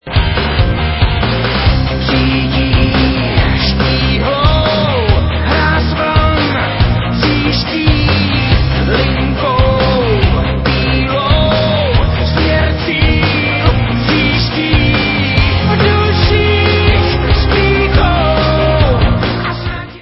vocals, guitars
drums, vocals
keyboards
bass